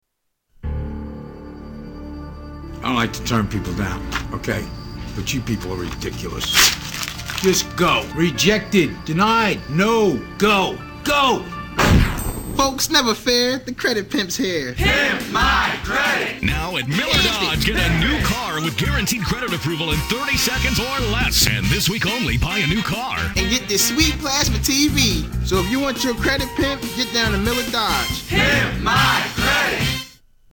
Category: Television   Right: Personal
Tags: Television Awful Commericals Bad Commercials Commercials Funny